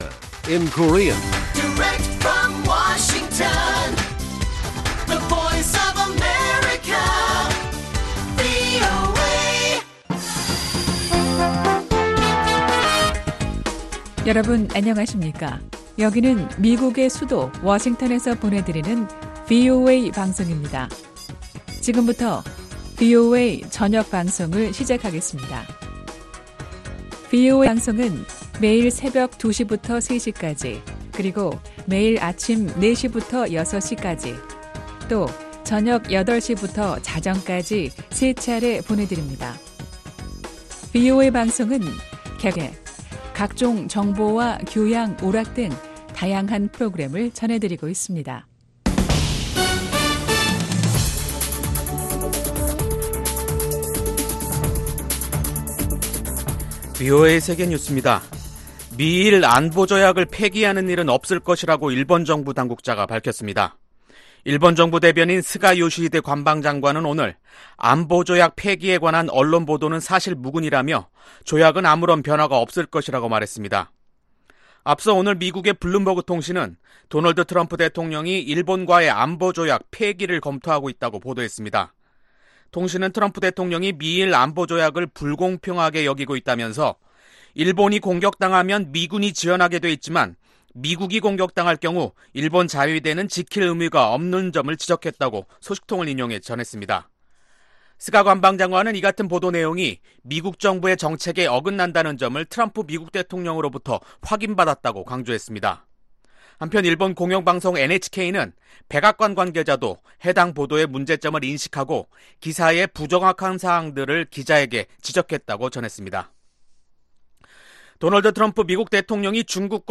VOA 한국어 간판 뉴스 프로그램 '뉴스 투데이', 2019년 6월 25일 1부 방송입니다. 미국 정보기관들은 북한 김정은 국무위원장이 비핵화에 대한 준비가 돼 있지 않은 것으로 보고 있다고 미 국방정보국장이 밝혔습니다. 미국의 전문가들은 미-북 정상의 친서 왕래에도 불구하고 비핵화 협상의 진전을 판단하기엔 아직 이르다는 입장을 보였습니다.